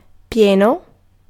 Ääntäminen
US : IPA : [ɹɪ.ˈplit]